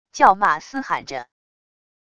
叫骂嘶喊着wav音频